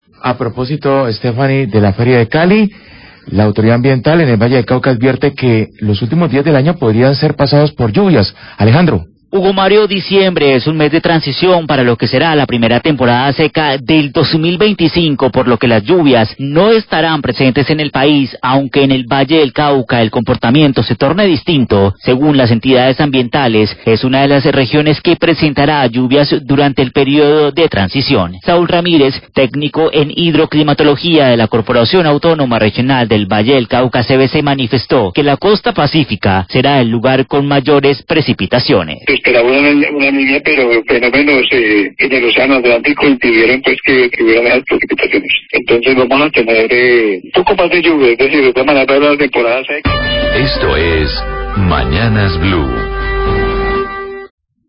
Radio
(audio cortado desde origen para dar paso al himno)